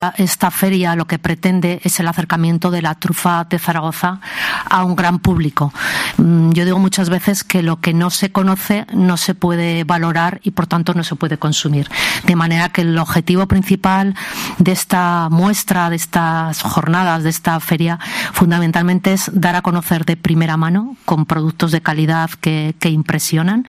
La diputada de Turismo, Cristina Palacín, explica el objetivo de la III Feria de la Trufa Negra